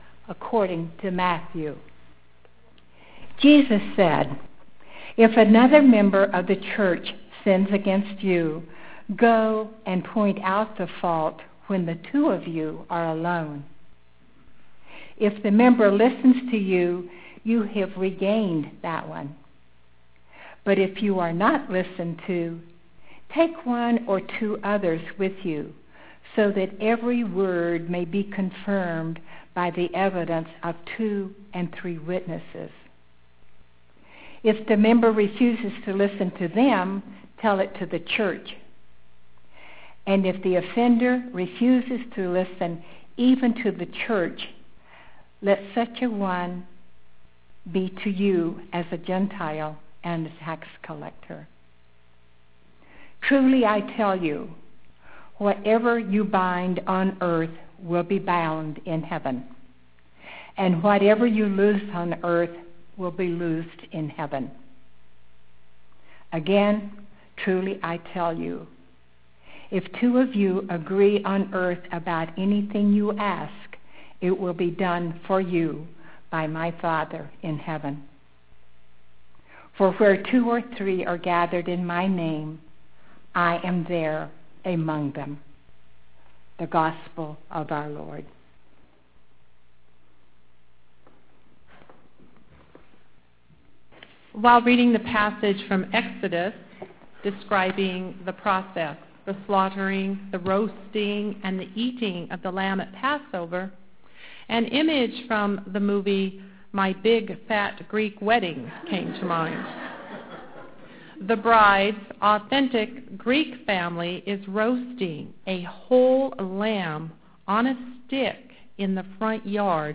Click on the white triangle in the black bar-shaped player below to listen to the scripture and sermon now or click on the link labeled: Download below the player to save an audio mp3 file for listening later.